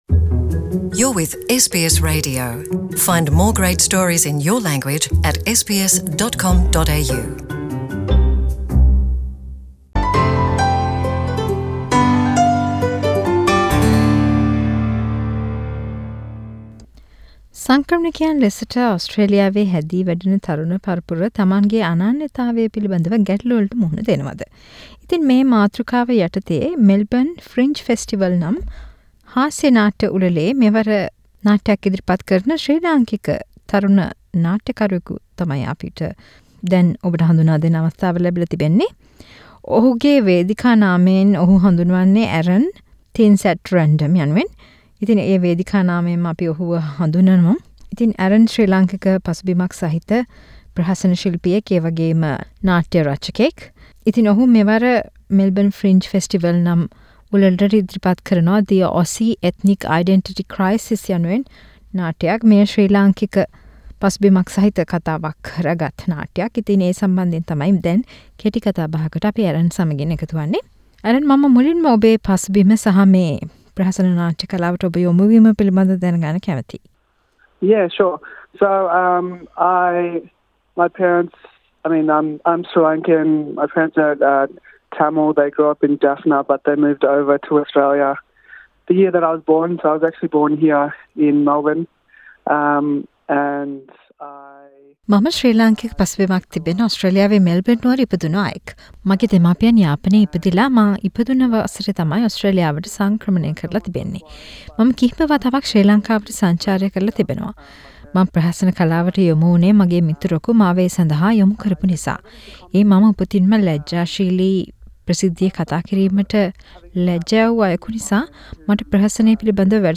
සිදු කළ කතා බහක්.